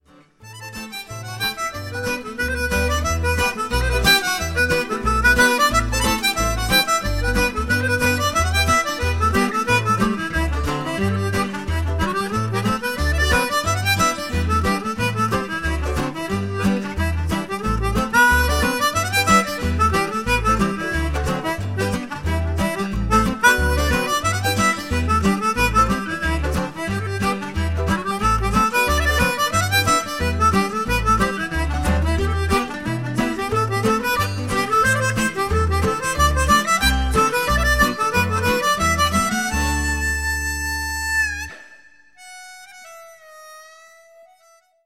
Slide-Diatonic in action